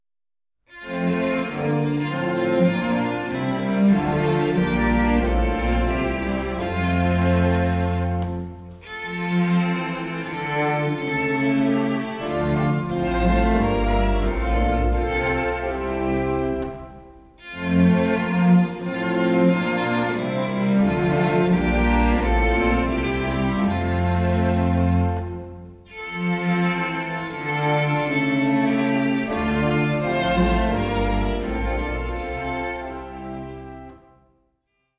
Streichensemble, Orgel
• kurzweilige Zusammenstellung verschiedener Live-Aufnahmen